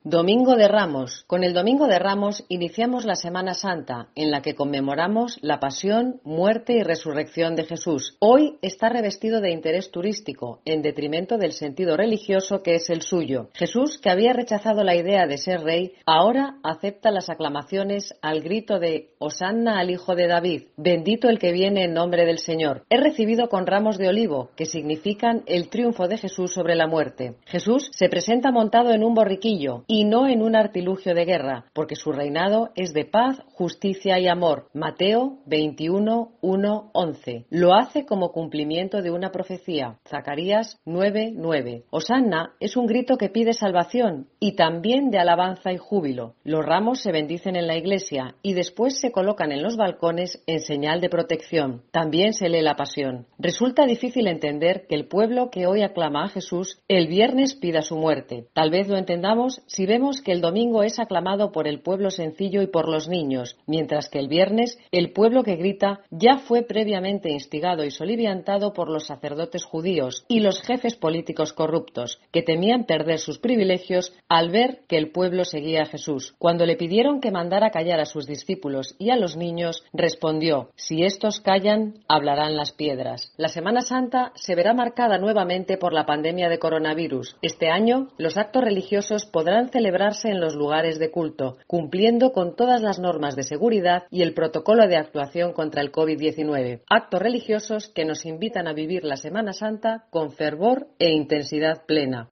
OPINIÓN-SOCIEDAD